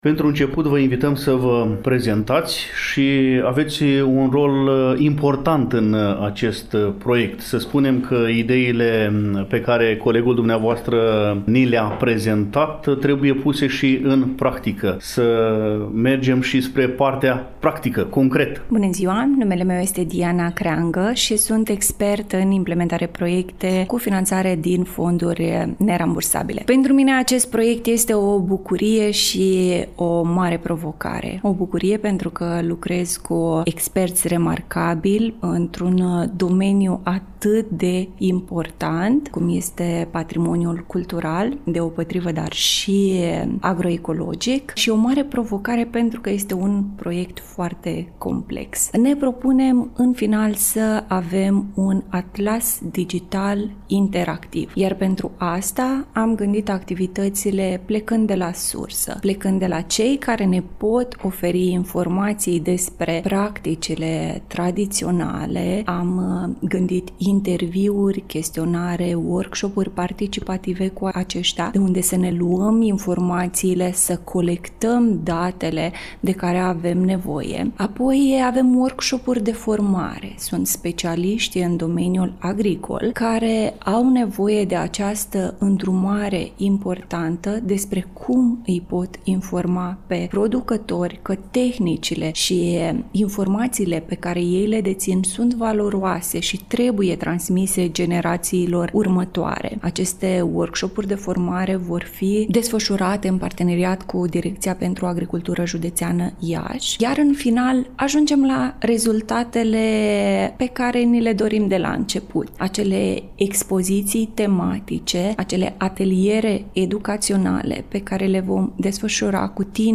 Pentru a afla amănunte concrete atât despre obiectivele proiectului, cât și despre principalele activități din cadrul acestuia, am invitat la dialog pe membrii echipei care se ocupă de implementare.